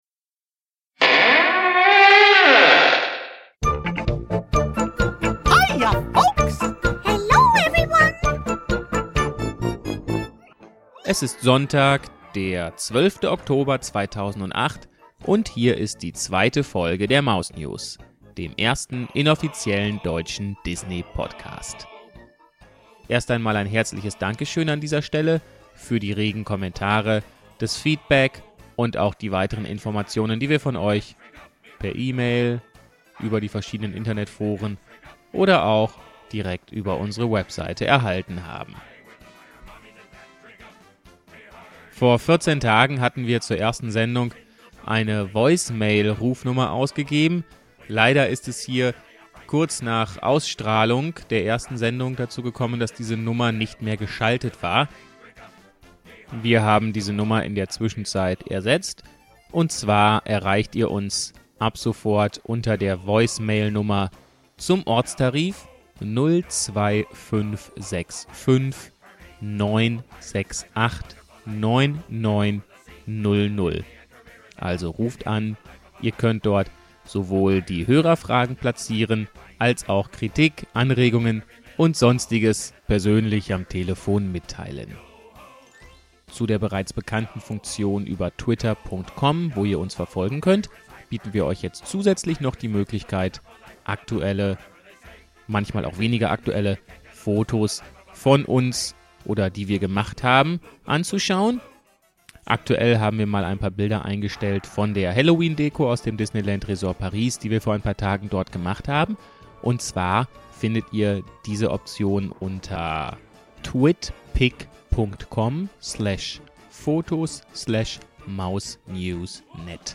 – DisneyPark News – Film- und DVD-News – MouseNews live aus dem Disneyland Resort Paris – Underdog auf Blu-ray – The Nightmare before Christmas auf Blu-ray – Unsere Empfehlungen